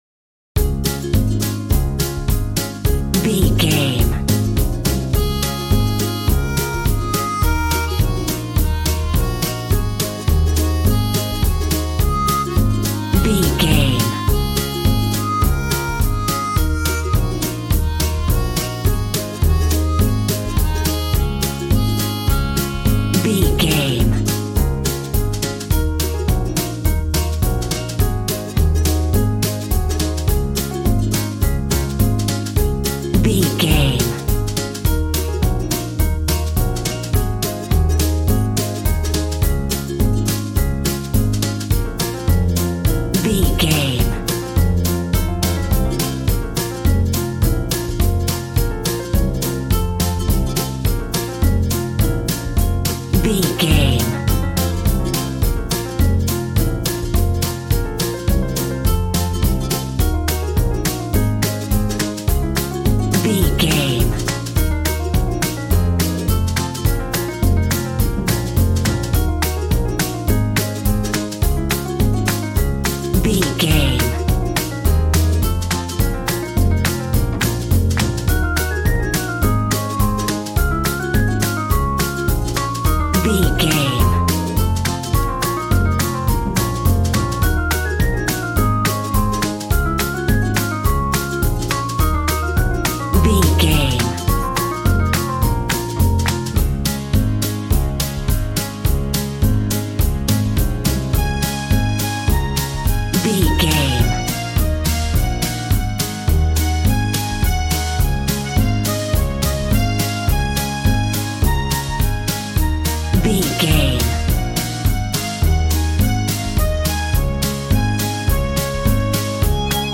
Upbeat, uptempo and exciting!
Aeolian/Minor
cheerful/happy
bouncy
electric piano
electric guitar
drum machine